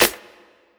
Waka SNARE ROLL PATTERN (61).wav